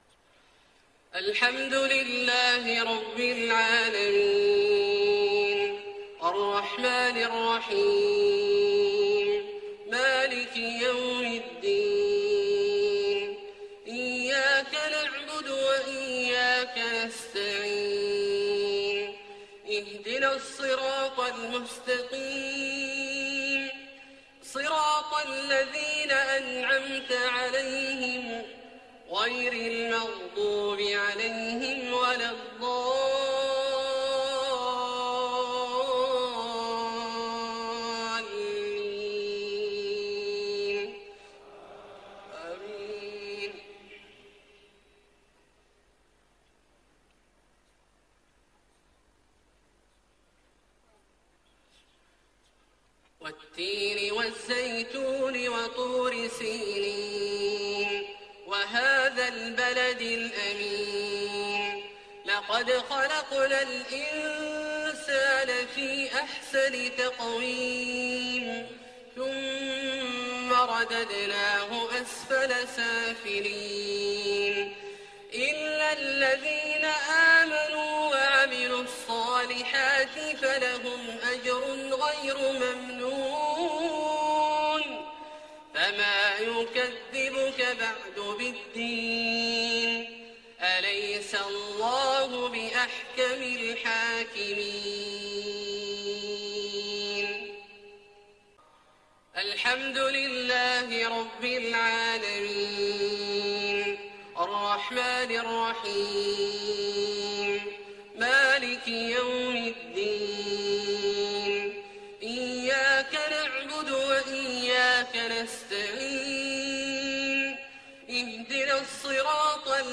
عشاء 15 محرم 1433هـ سورتي التين والزلزلة > ١٤٣٣ هـ > الفروض - تلاوات عبدالله الجهني